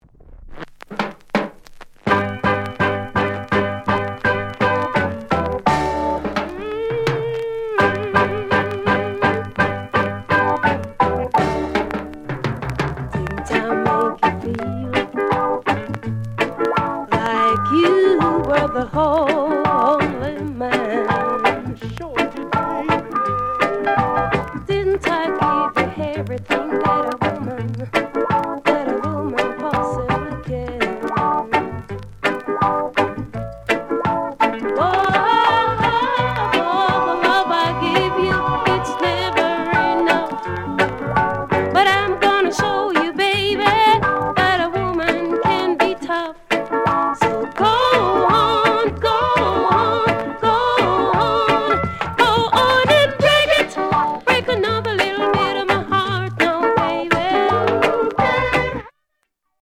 NICE SOUL COVER